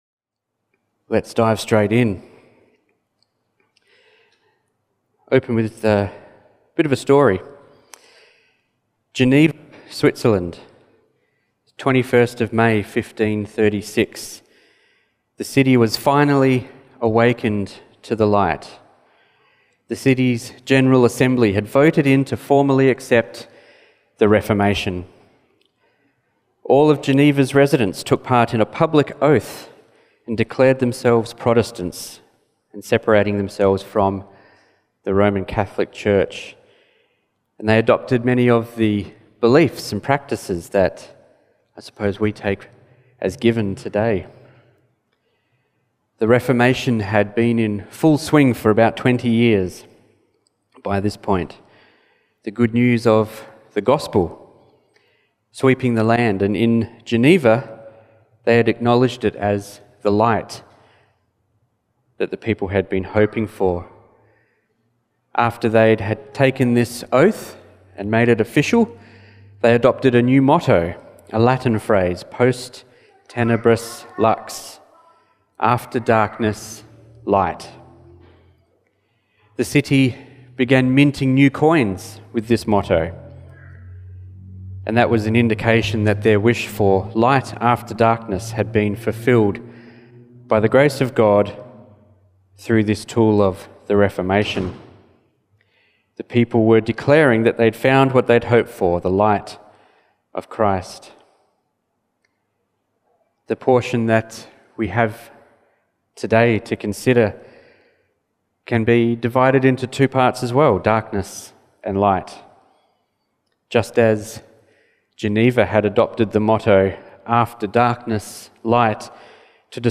Be Faithful Not Faithless Passage: Isaiah 8:15-9:7 Service Type: Family Service Topics